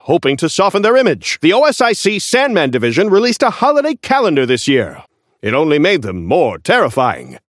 Newscaster voice line - Hoping to soften their image, the OSIC Sandman division released a holiday calendar this year!
Newscaster_seasonal_haze_unlock_01_comp.mp3